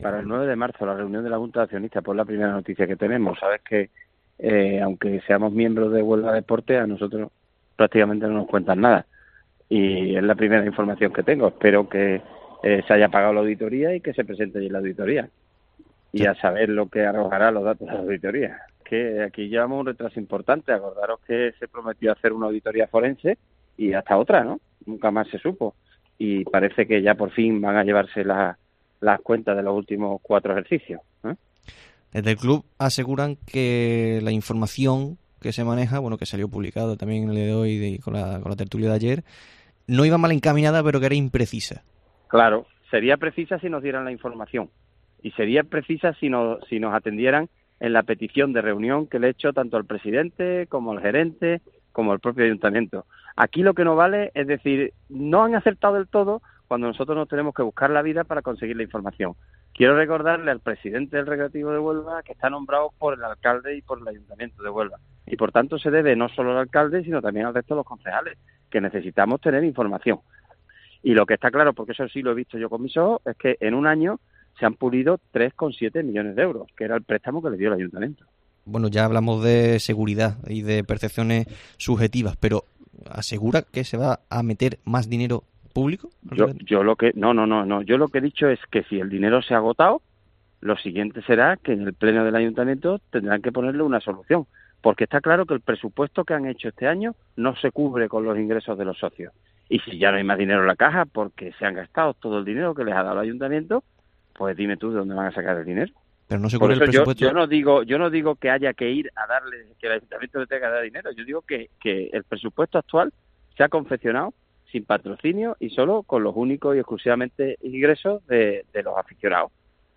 Jaime Pérez, portavoz del PP en el Ayuntamiento de Huelva, atiende a la llamada de COPE Huelva donde asegura de la inestabilidad económica del Decano.